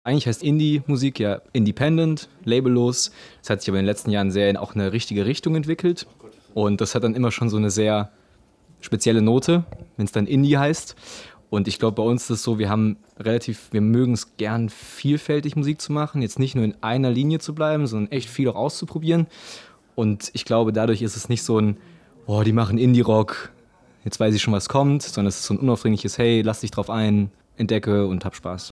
O_Ton_1.mp3